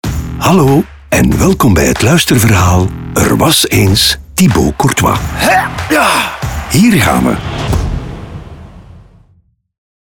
Een voorproevertje in de vorm van een audioboek